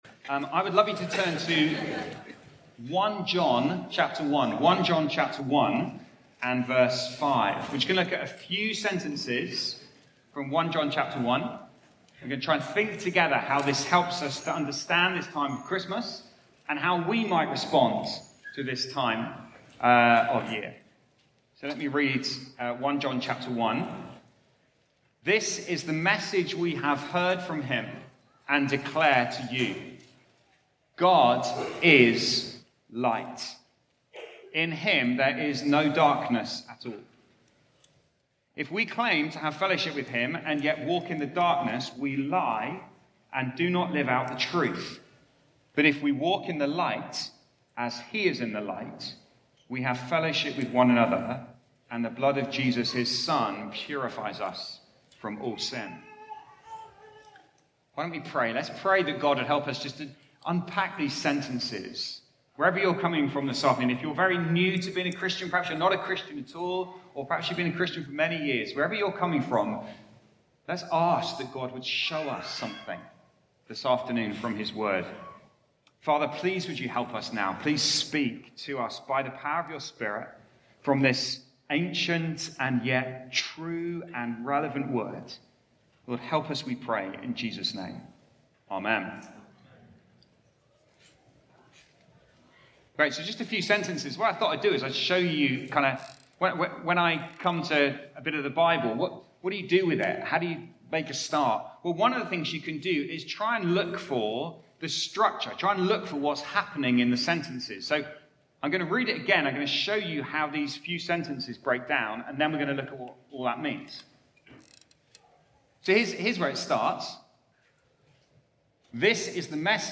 Sermon-Audio-21-December.mp3